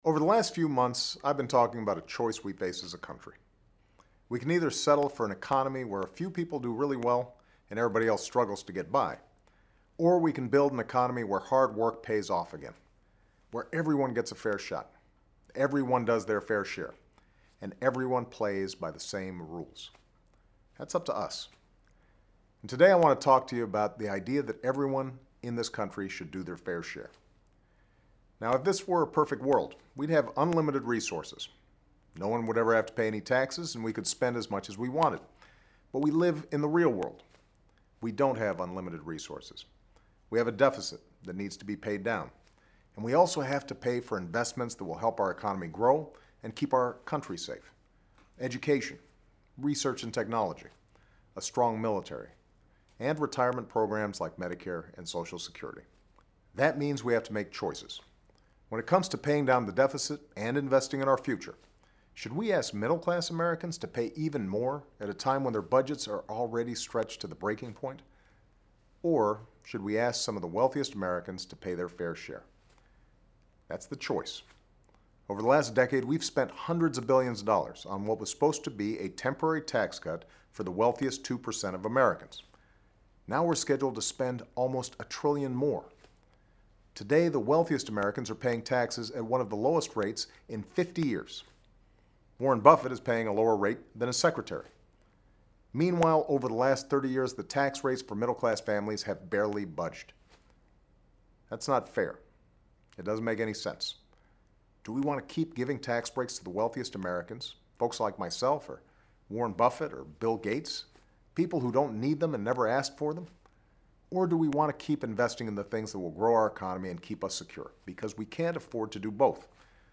Remarks of President Barack Obama
Weekly Address